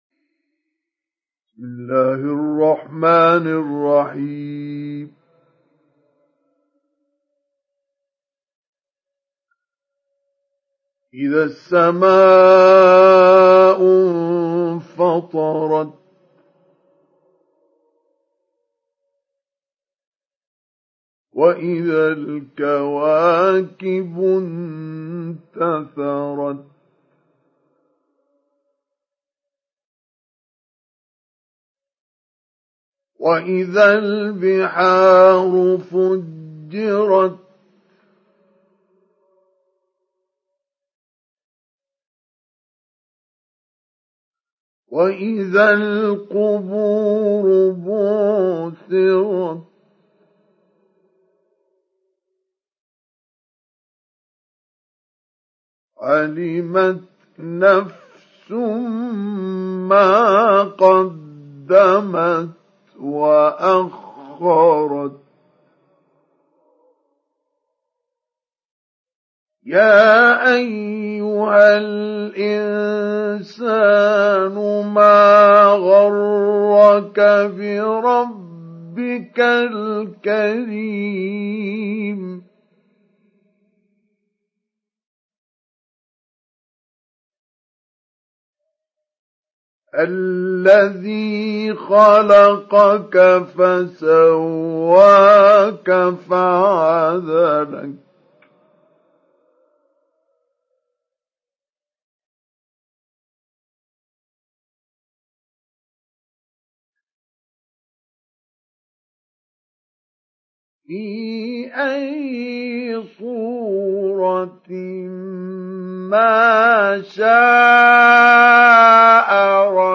Download Surat Al Infitar Mustafa Ismail Mujawwad